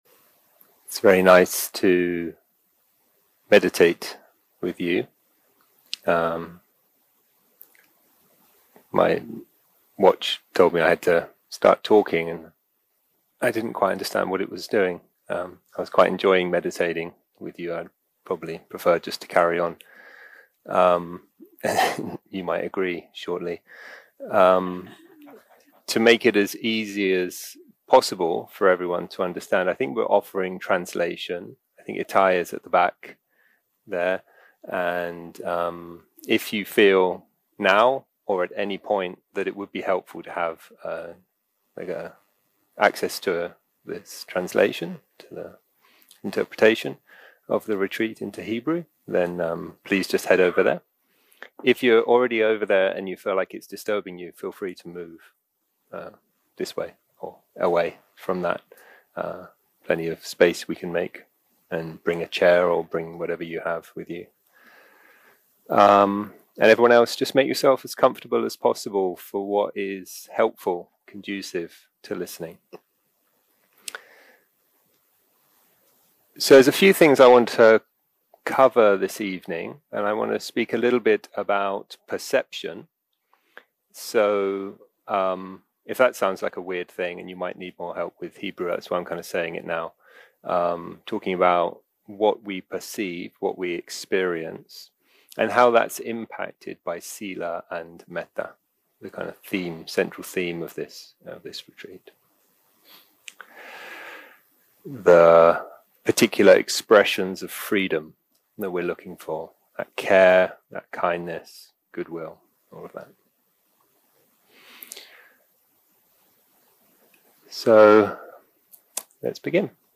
Dharma talk language